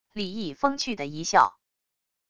李毅风趣的一笑wav音频